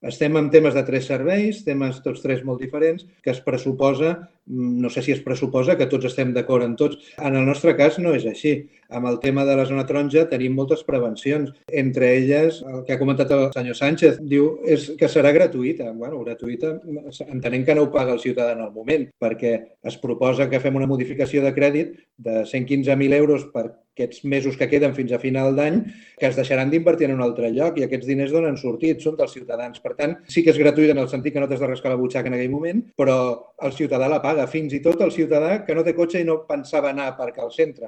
El motiu, explicat pel portaveu de Junts per Cerdanyola,
Joan Sánchez Braut (JxC)